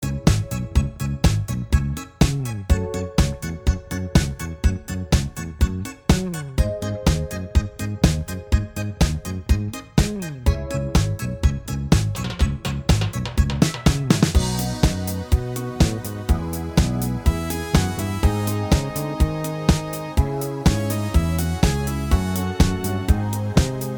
Minus All Guitars Disco 3:37 Buy £1.50